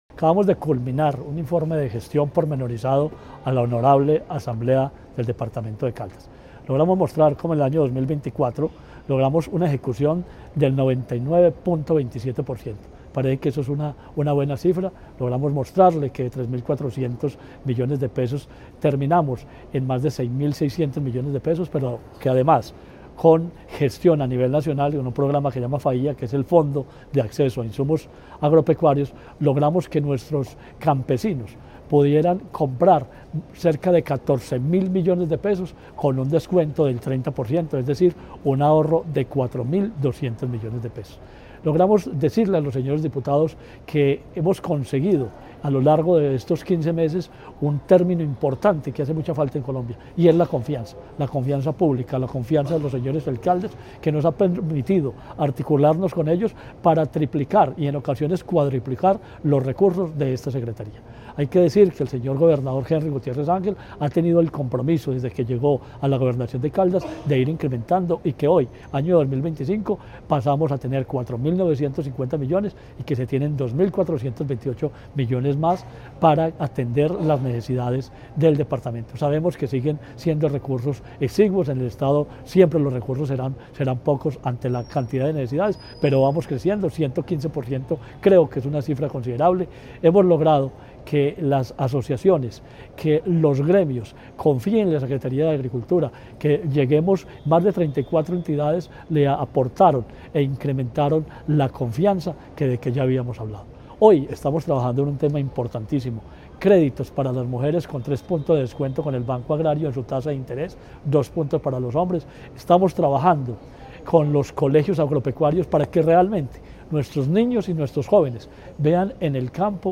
Ante la Asamblea Departamental, el secretario de Agricultura y Desarrollo Rural de Caldas, Marino Murillo Franco, presentó el informe de gestión correspondiente al año 2024, destacando una ejecución presupuestal del 99.27% sobre los $4.400 millones asignados a su despacho.
Marino Murillo Franco, secretario de Agricultura y Desarrollo Rural de Caldas.